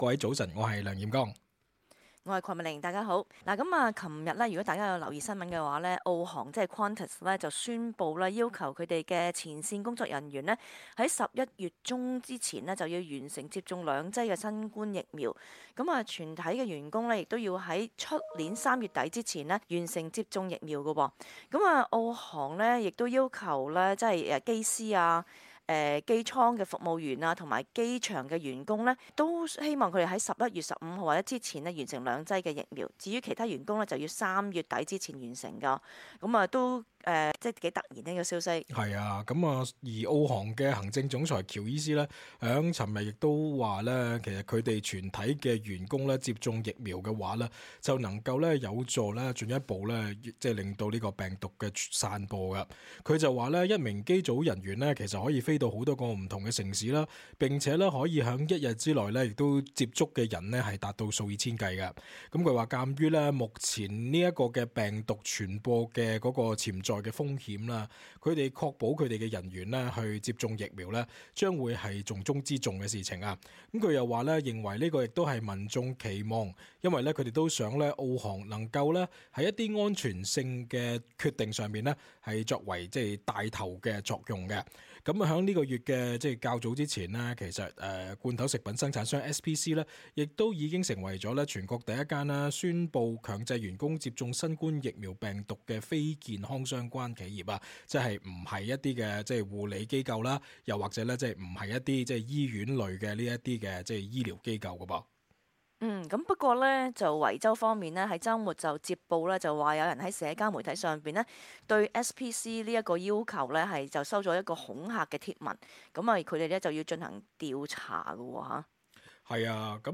talkback_cantonese_-_final_-_aug_19.mp3